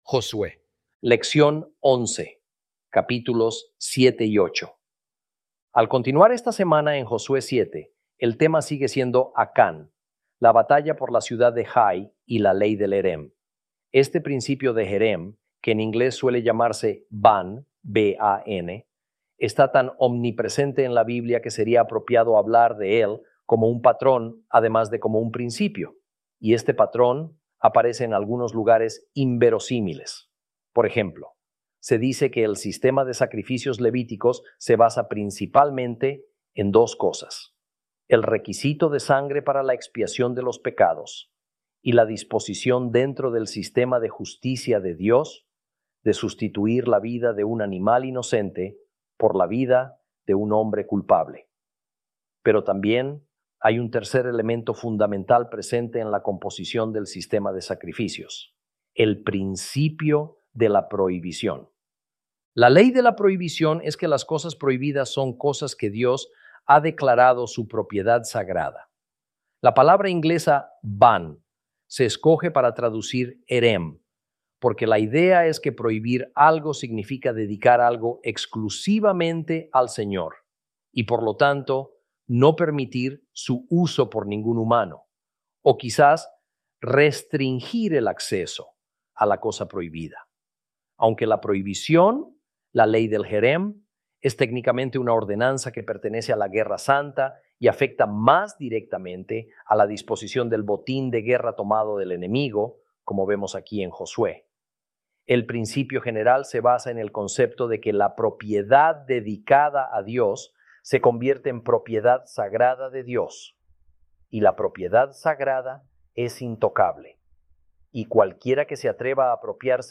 Lección 11 - Josué Ch 7-8 - Torah Class